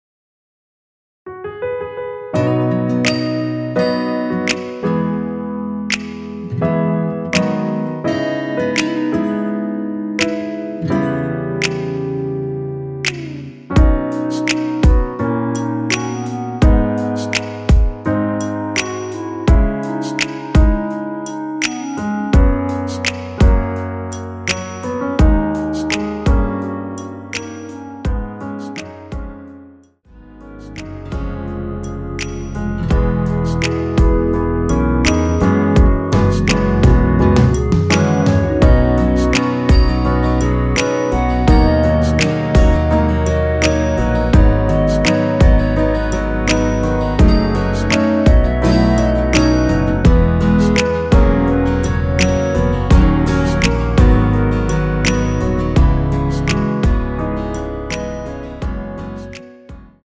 원키에서(-3)내린 멜로디 포함된 MR입니다.
앞부분30초, 뒷부분30초씩 편집해서 올려 드리고 있습니다.
중간에 음이 끈어지고 다시 나오는 이유는